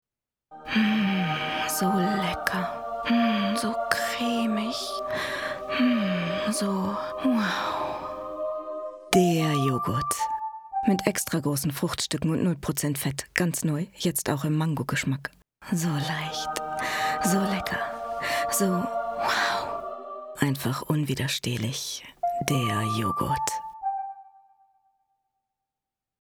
Markante warme, weiche Stimme mit Tiefe, die berührend und sinnlich sein kann.
Sprechprobe: Werbung (Muttersprache):